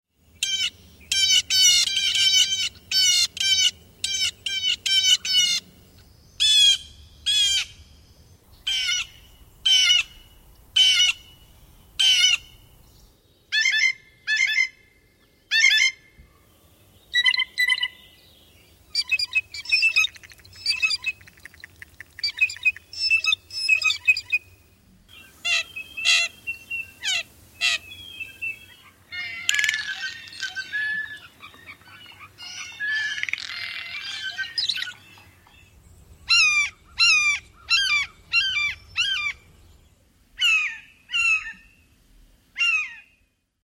Birdcalls
Blue Jay
bluejay.mp3